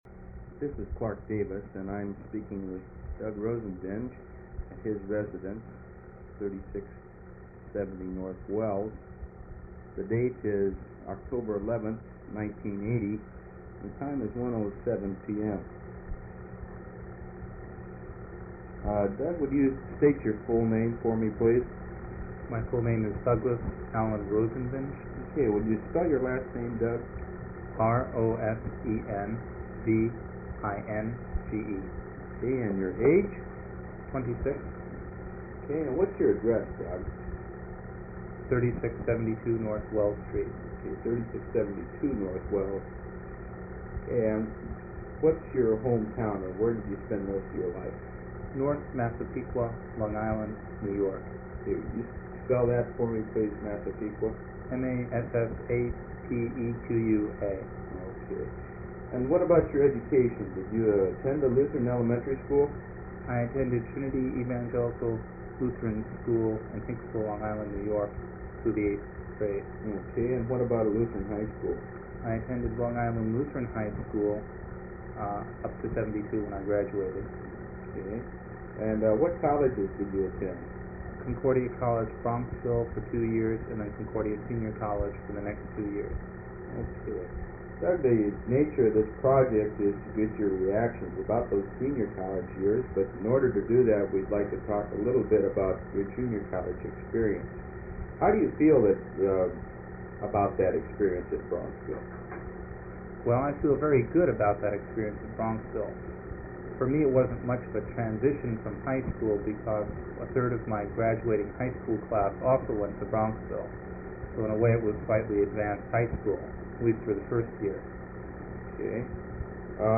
Kramer Chapel Sermon - March 29, 1982